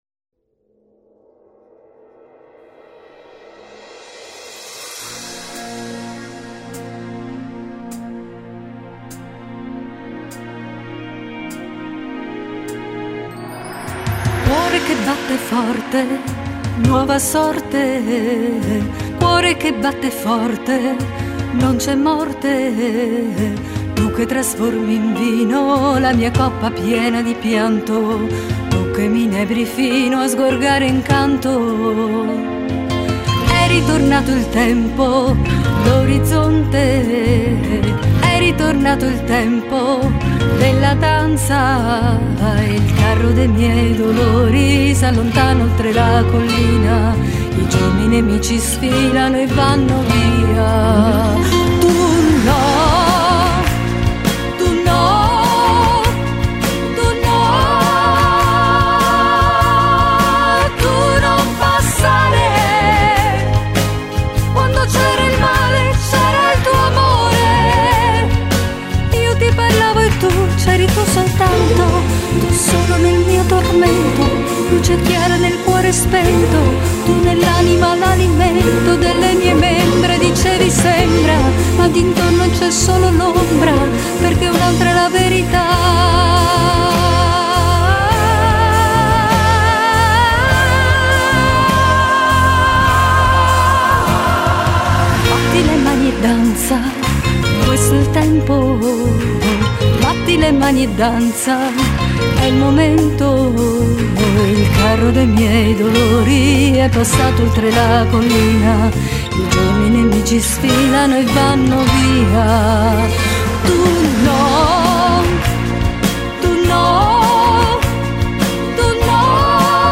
concerto che spesso faccio dal vivo